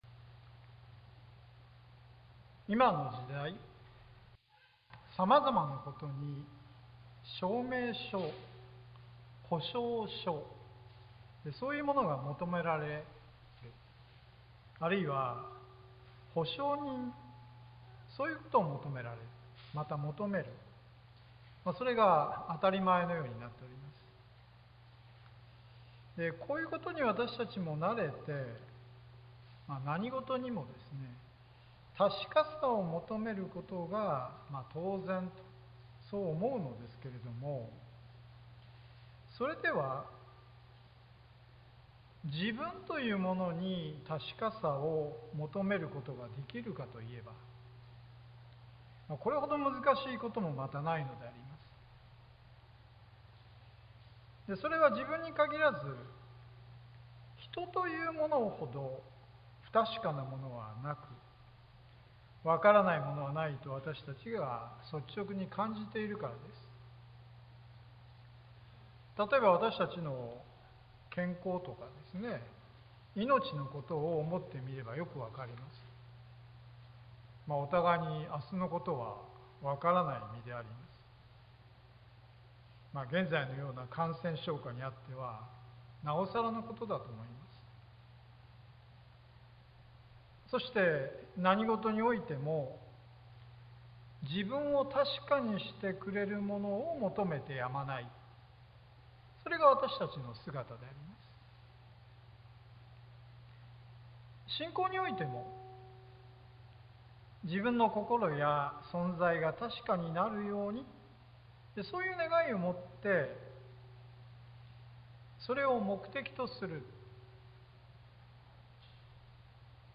sermon-2021-09-19